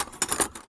R - Foley 189.wav